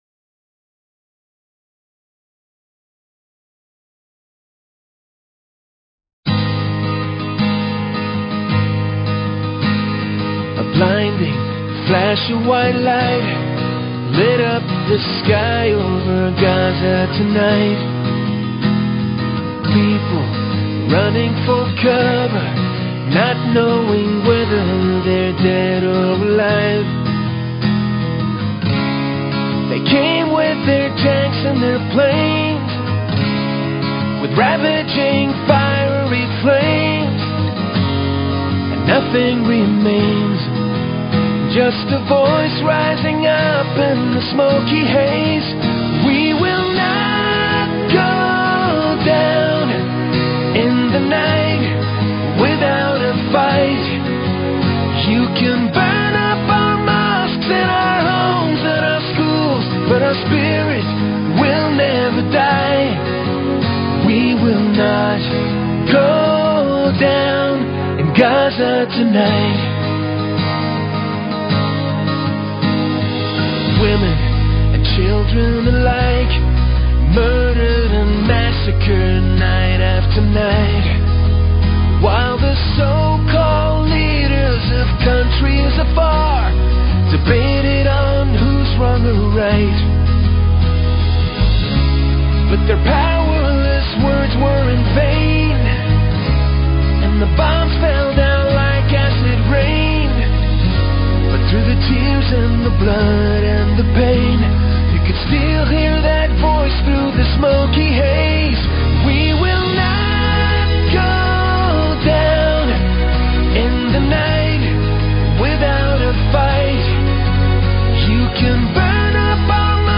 Talk Show Episode, Audio Podcast
Guest, Cynthia McKinney